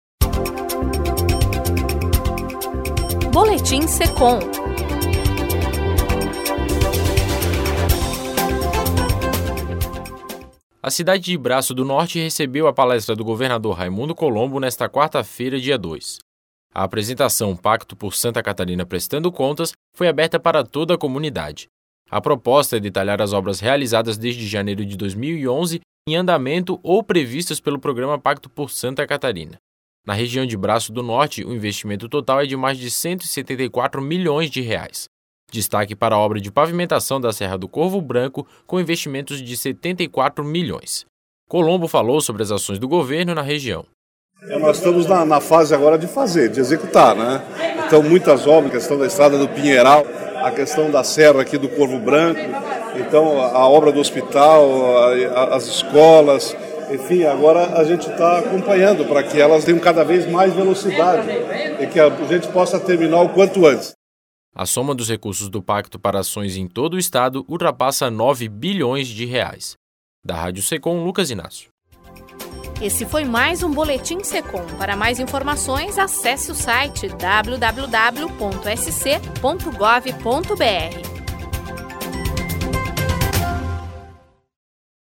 Colombo falou sobre as ações do governo na região./